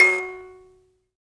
Index of /90_sSampleCDs/Roland LCDP11 Africa VOL-1/PLK_Buzz Kalimba/PLK_HiBz Kalimba